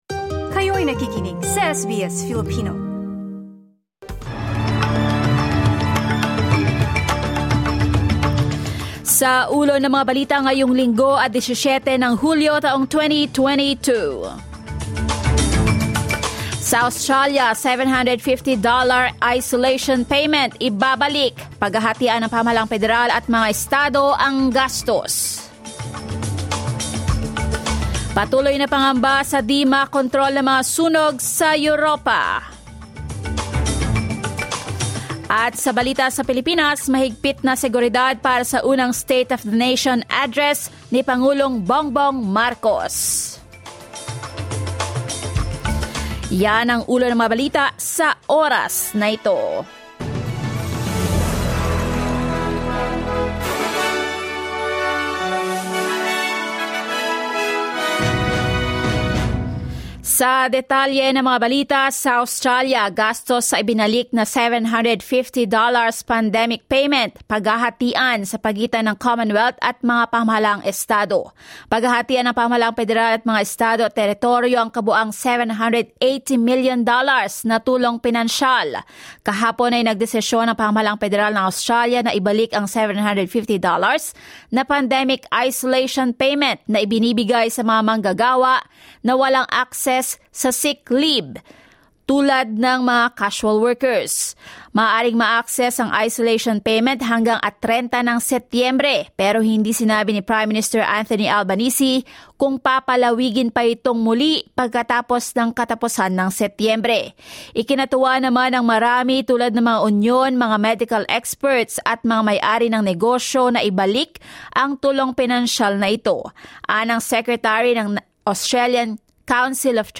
SBS News in Filipino, Sunday 17 July